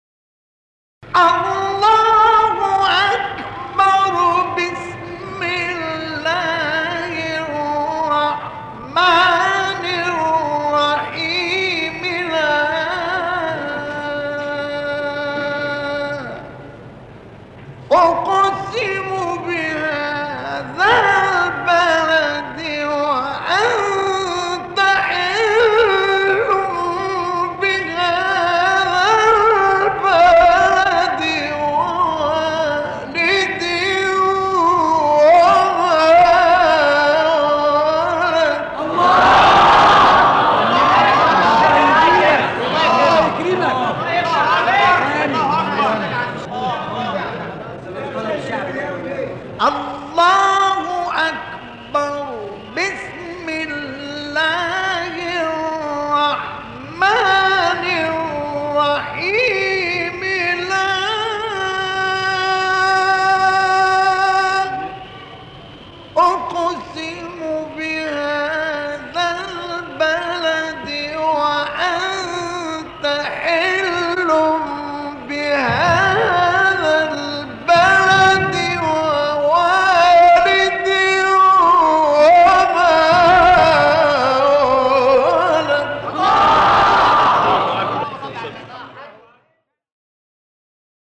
قطعه تلاوت مصطفی اسماعیل